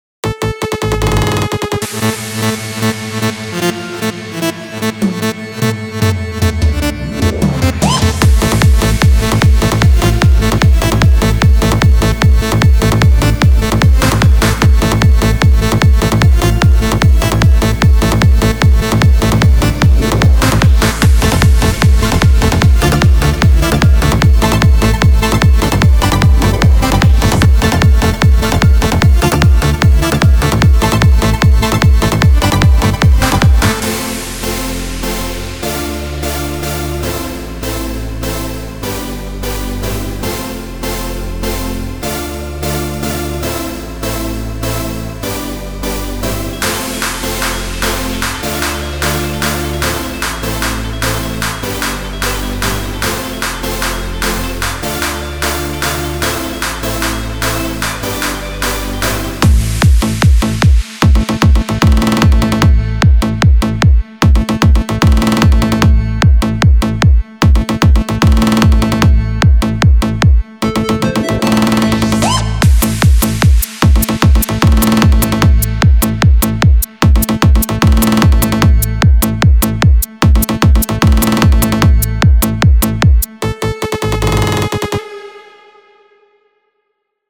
בנוי טוב מלודיה שחוזרת על עצמה ומשעממת